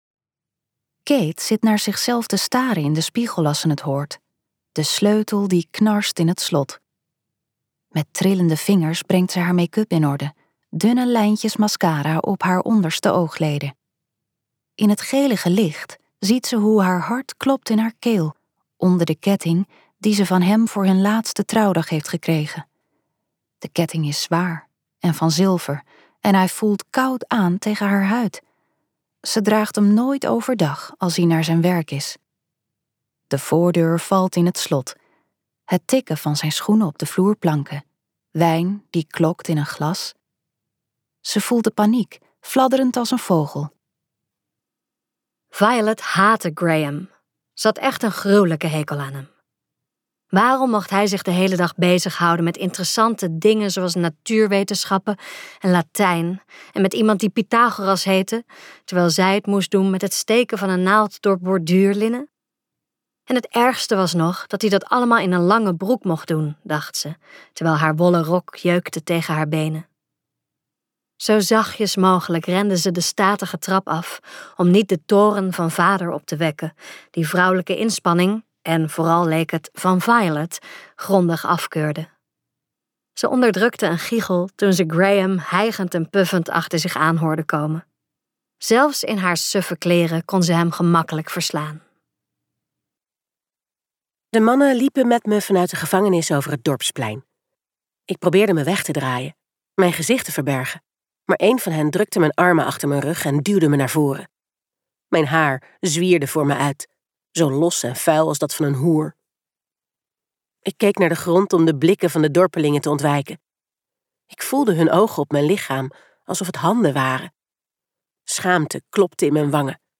KokBoekencentrum | De weyward vrouwen luisterboek